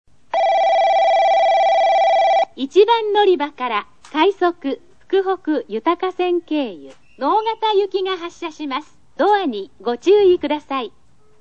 スピーカー：ソノコラム
発車放送（快速・直方）　(56KB/11秒)   九州主要 ＭＤ